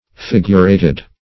Figurated \Fig"ur*a`ted\, a. Having a determinate form.